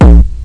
bdrum-samples
1 channel
indian-overdrive.mp3